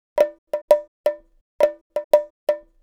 Bongo 06.wav